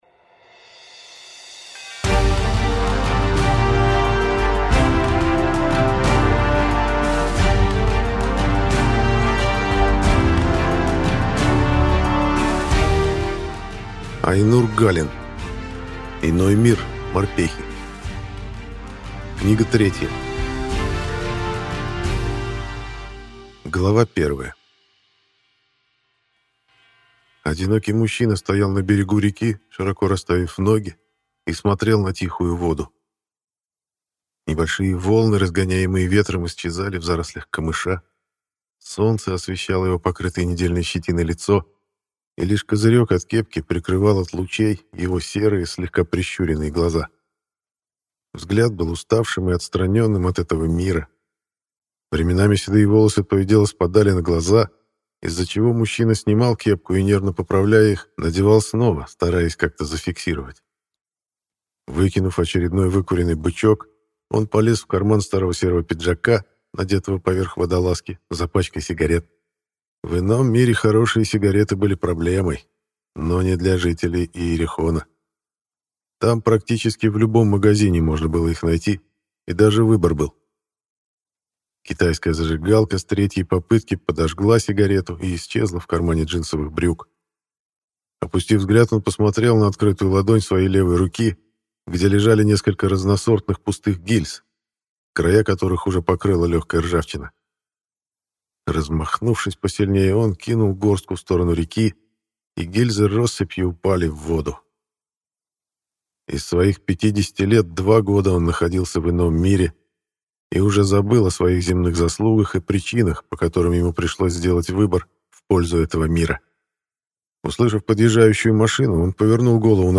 Аудиокнига Иной мир. Морпехи. Книга третья | Библиотека аудиокниг